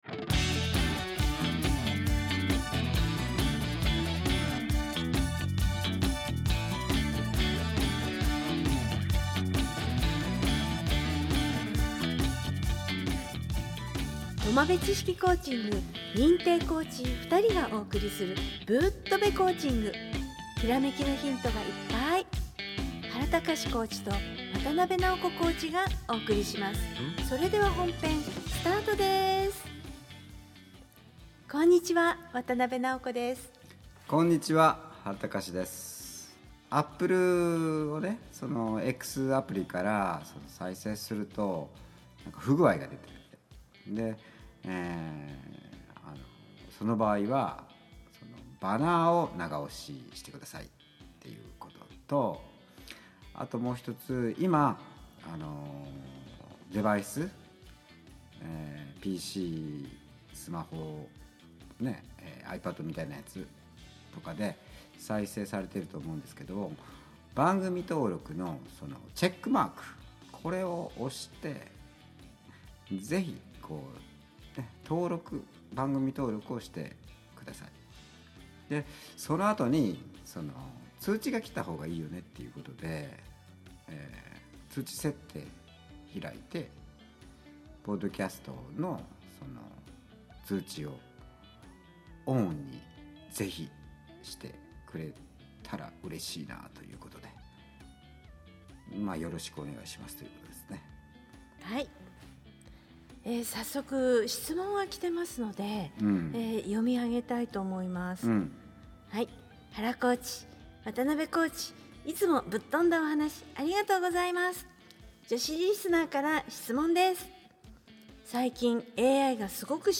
苫米地式コーチング認定コーチ二人がお送りするぶっ飛べコーチング。